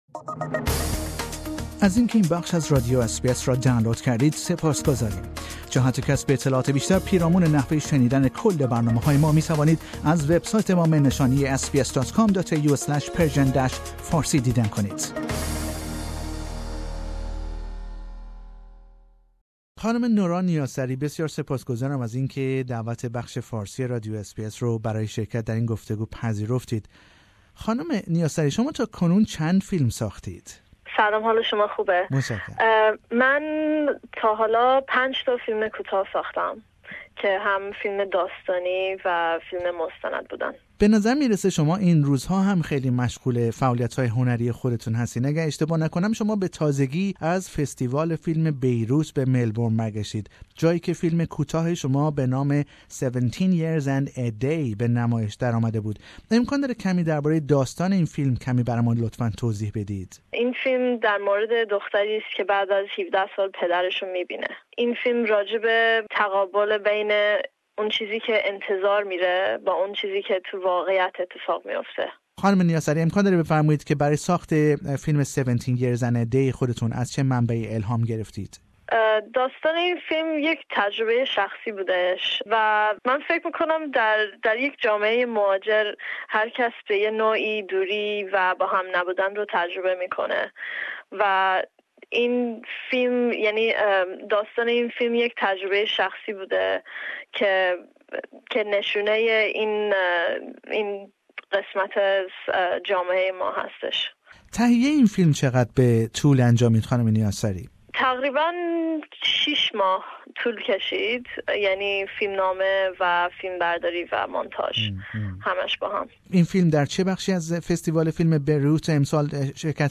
او در گفتگویی کوتاه با برنامه فارسی رادیو اس بی اس از کارهای هنری خود و نیز فیلم کوتاهش به نام سیمرغ سخن می گوید